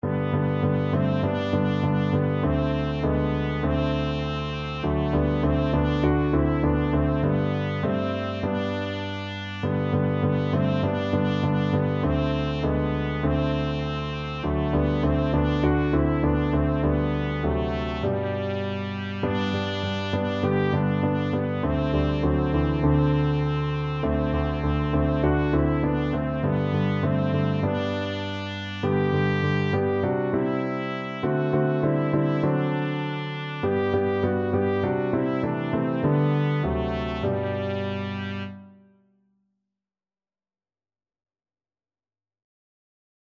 Classical Excell, Edwin Count Your Blessings Trumpet version
Trumpet
G major (Sounding Pitch) A major (Trumpet in Bb) (View more G major Music for Trumpet )
4/4 (View more 4/4 Music)
Classical (View more Classical Trumpet Music)